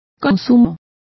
Complete with pronunciation of the translation of intakes.